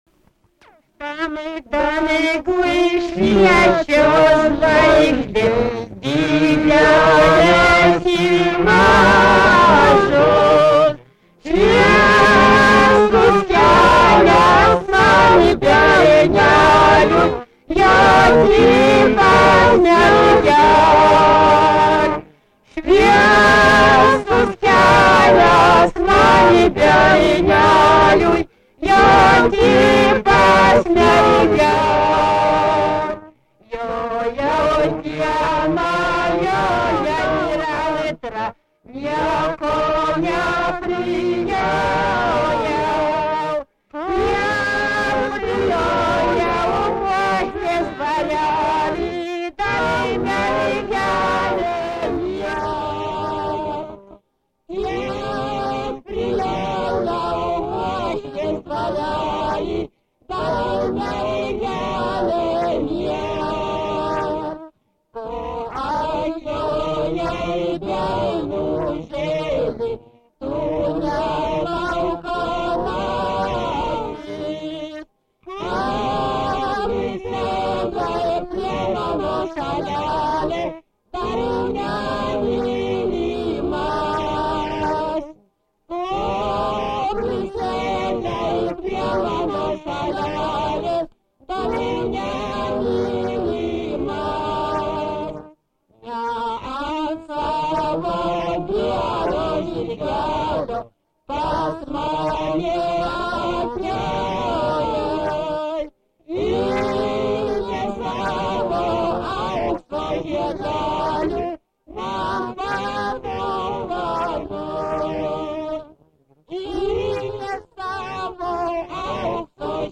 Dalykas, tema daina, kalendorinių apeigų ir darbo
Erdvinė aprėptis Knystuškės (Knistushki), Baltarusija
Atlikimo pubūdis vokalinis